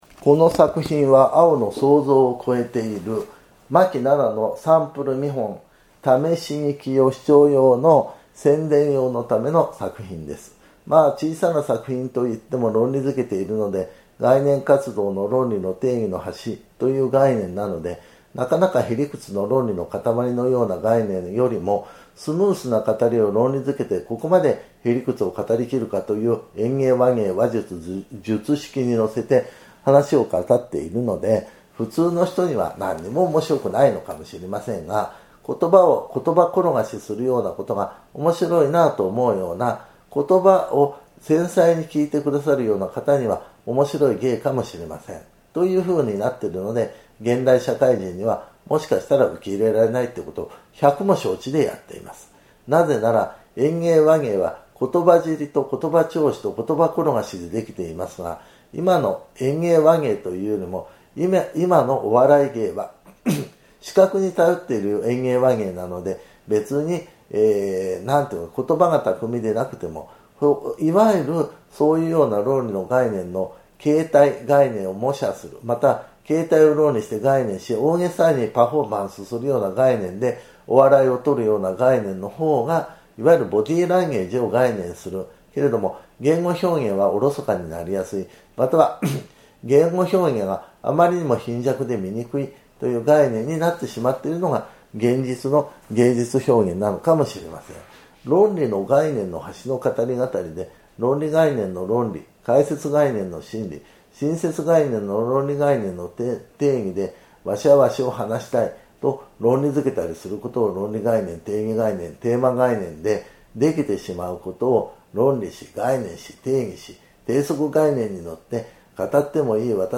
[オーディオブック] 講談 「あおの想像を超えている。」巻7